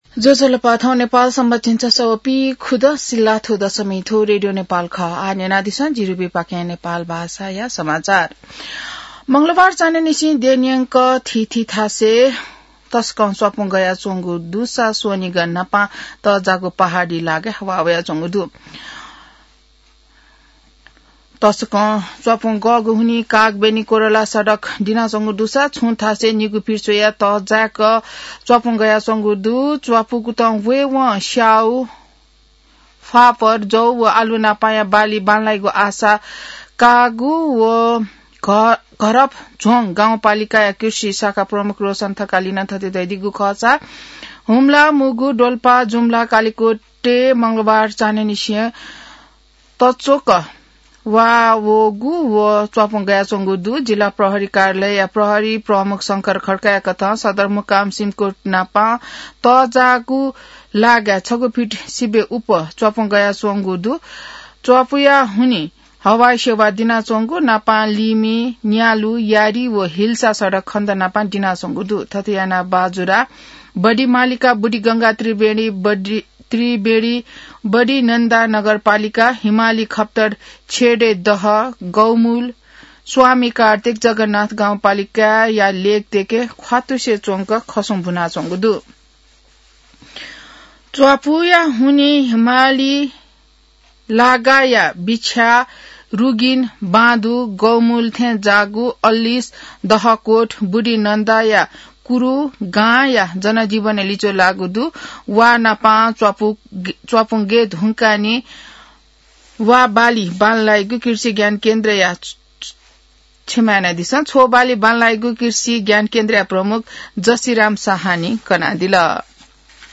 नेपाल भाषामा समाचार : १४ माघ , २०८२